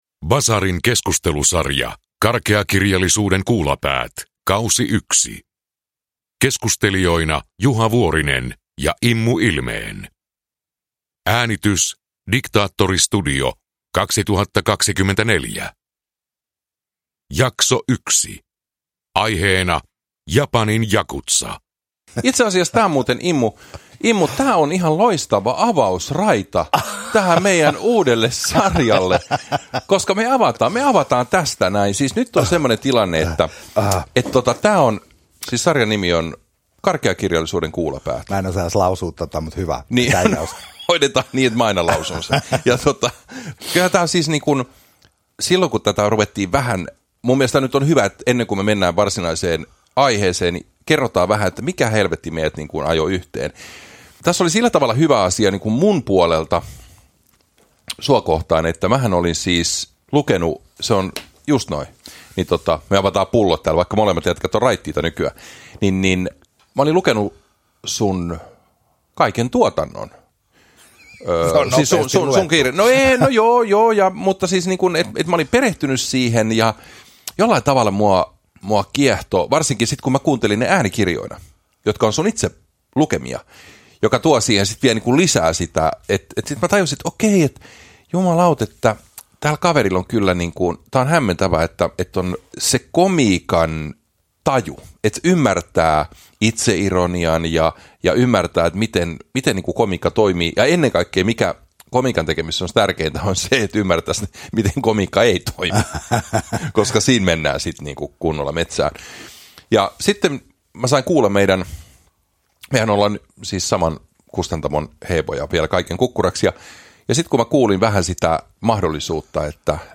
Karkeakirjallisuuden kuulapäät K1 – Ljudbok